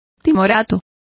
Complete with pronunciation of the translation of timorous.